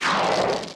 fireball_launch.mp3